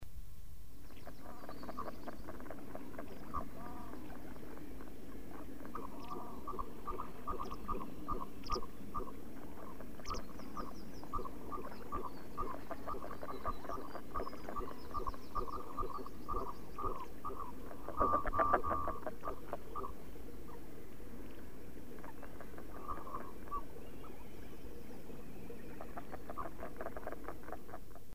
- Le chant de La Harle Bièvre